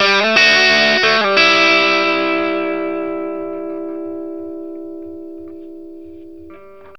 BLUESY1 A 60.wav